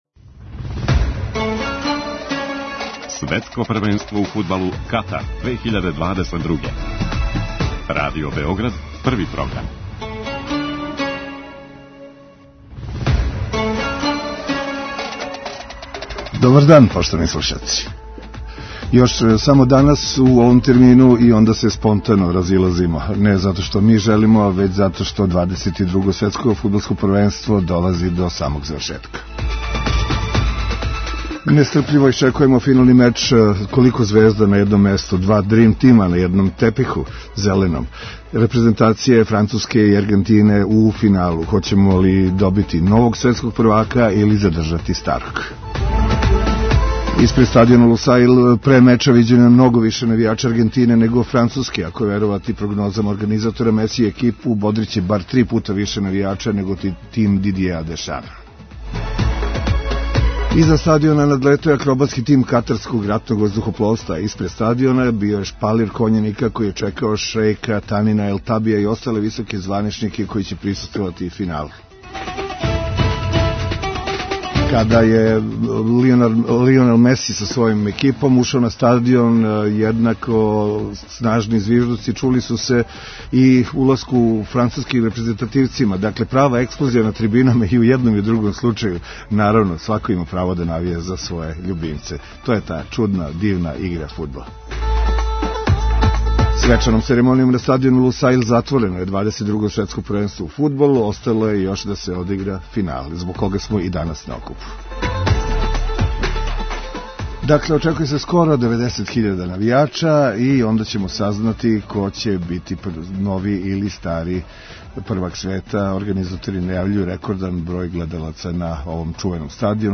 Гост коментатор у студију је некадашњи фудбалер сада тренер Иван Голац. Током финала чућемо бројне асове Српског фудбала који ће се укључити у програм. Анализираћемо ток меча, али и цео Мондијал са посебном освртом на учинак наше репрезентације.